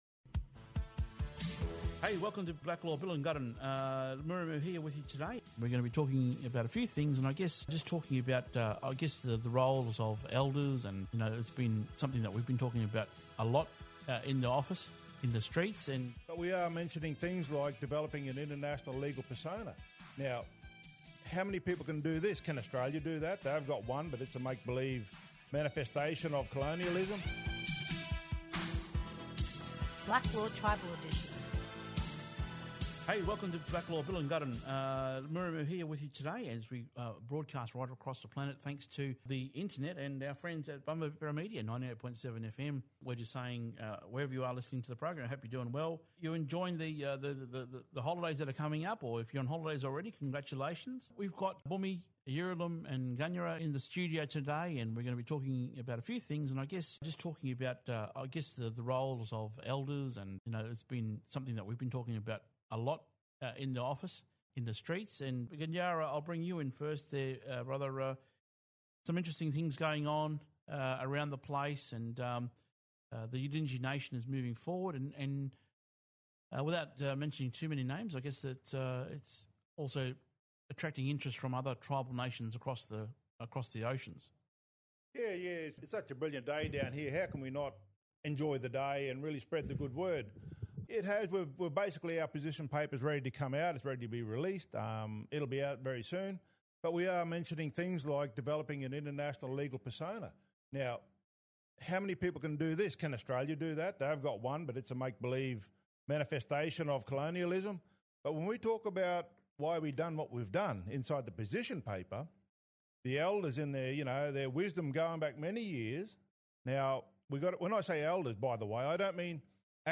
Discover how these ancient legal frameworks continue to shape identity, governance, and community cohesion today. Don’t miss this powerful discussion on sovereignty and legacy. Coming to you via NIRS – National Indigenous Radio Service only on BBM 98.7FM!